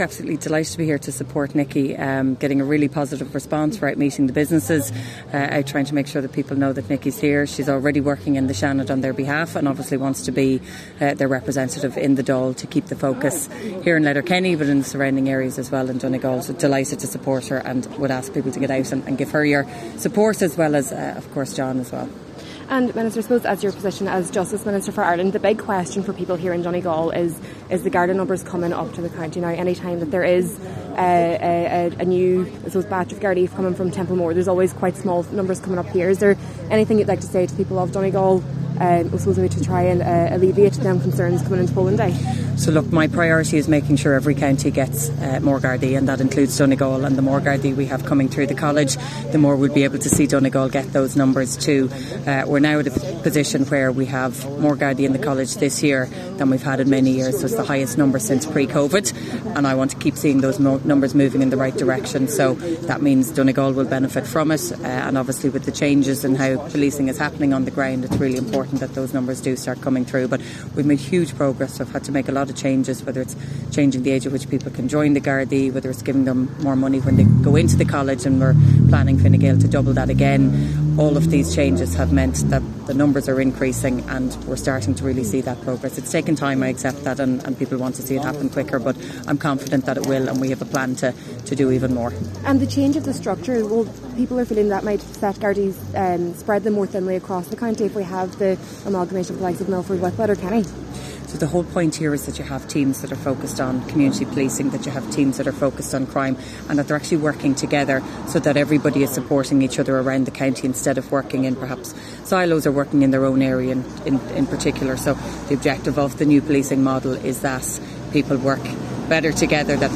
This morning, she was in Donegal Town with Fine Gael candidate John McNulty, before travelling to Letterkenny, where she is canvassing with Senator Nikki Bradley this afternoon.